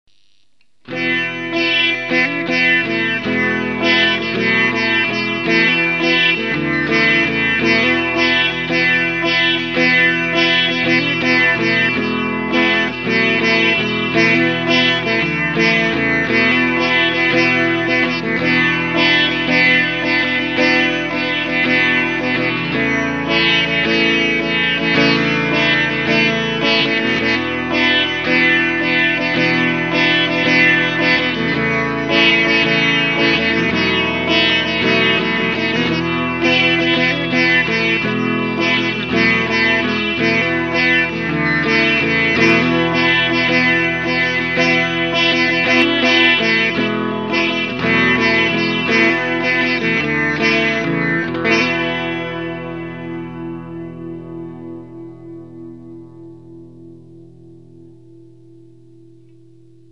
テレキャスターのブリッジを交換して エレキ・シタールに改造して弾いた音
なんともいえない音ですね。
sitarizer.mp3